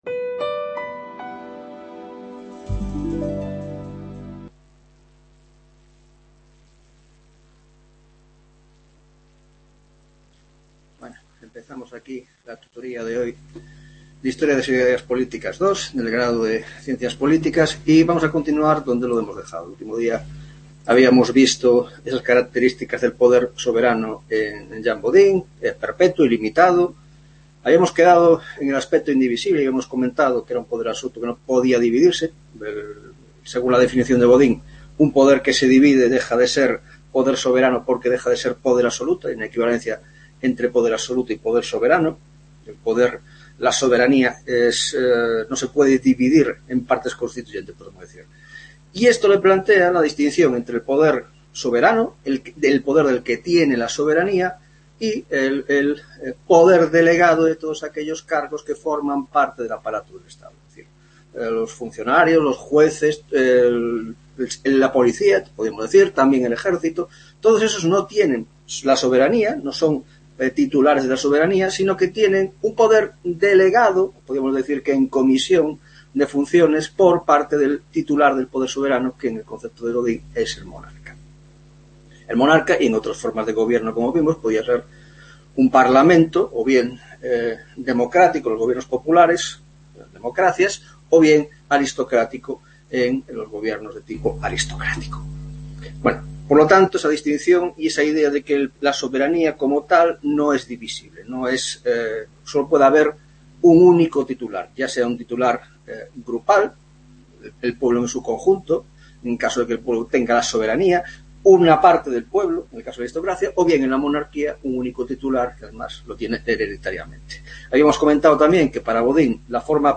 2ª Tutoria de Historia de las Ideas Políticas 2 (Grado de Ciencias Políticas)